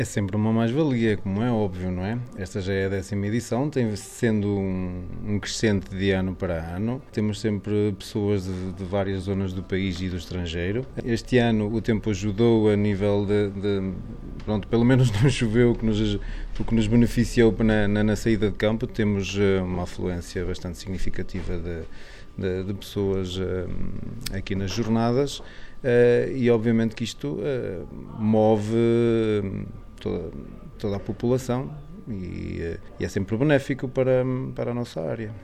O presidente de Junta de Freguesia de Vale Pradinhos, Jorge Pinto, salienta que há um aumento de participantes, fazendo um balanço muito positivo desta edição: